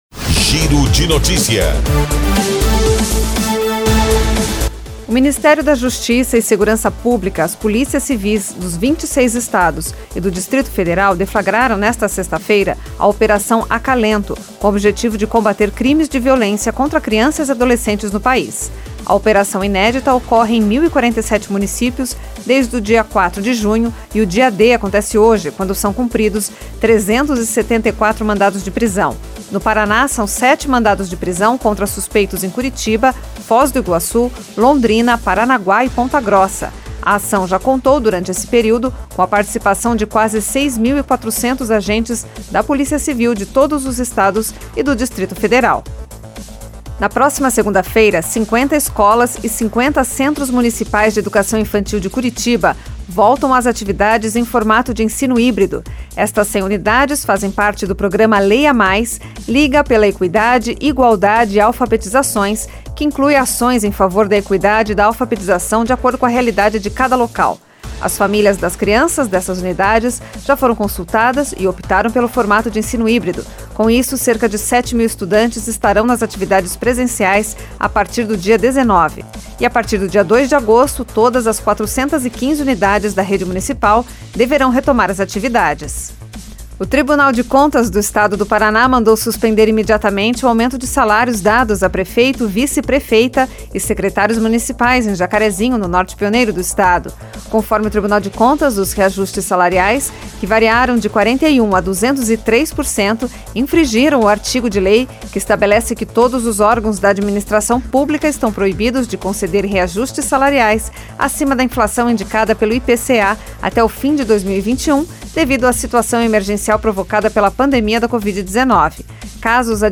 Giro de Notícias Tarde